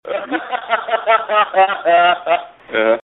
Tags: funny comedy podcast radio disgusting